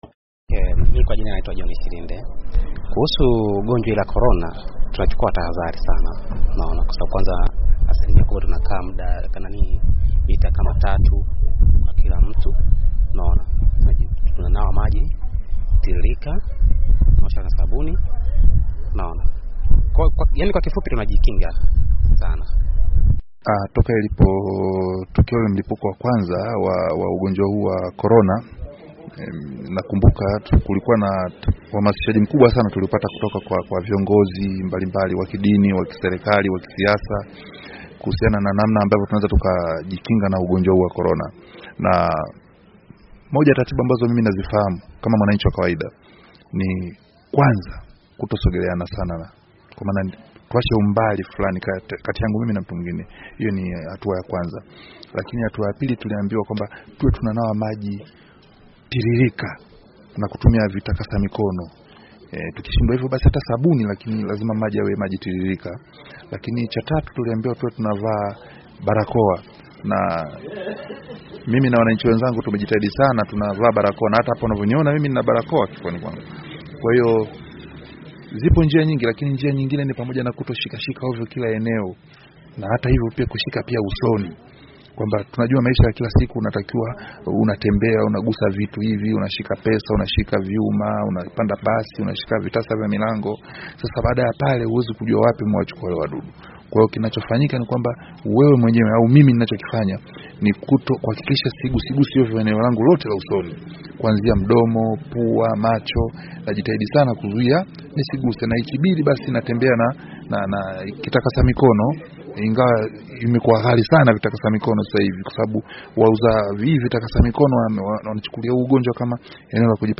COVID-19 : MAONI YA WANANCHI